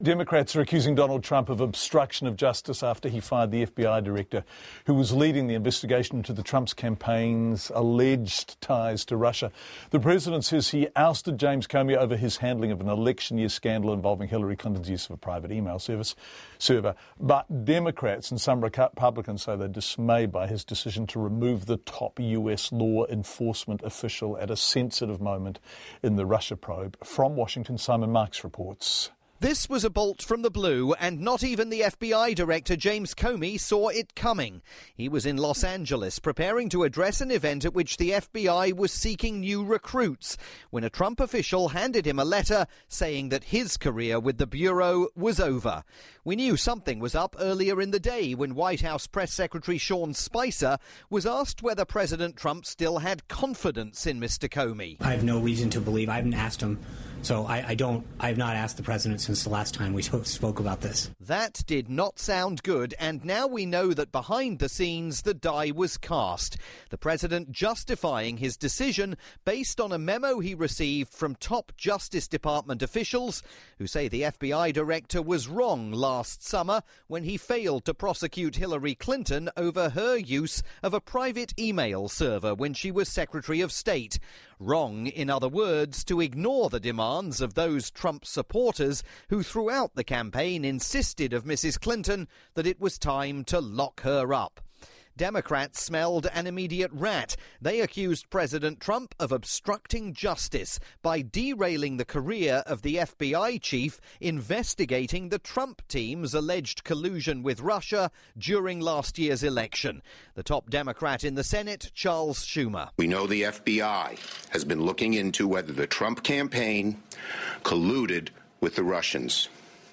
Here, via Radio New Zealand's flagship "Checkpoint" program.